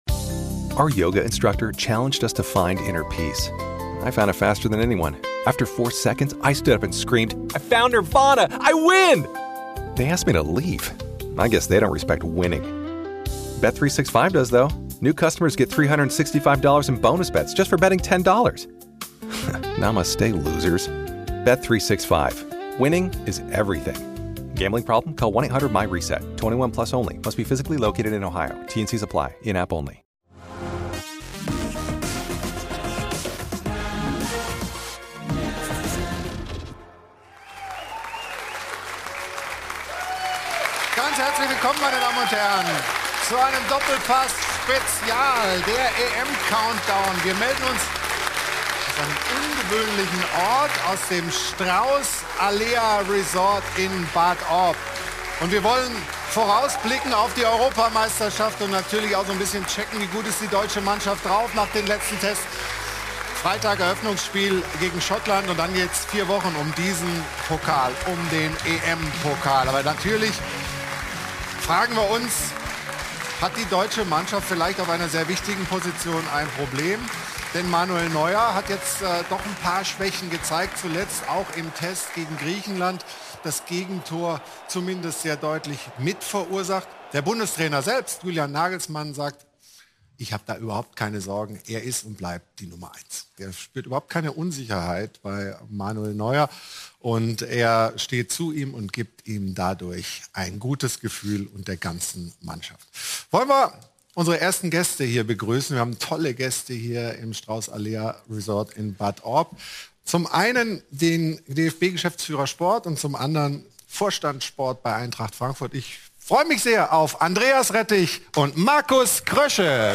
Doppelpass Spezial - Der EM-Countdown live aus dem STRAUSS ALEA RESORT Bad Orb ~ Der SPORT1 Doppelpass Podcast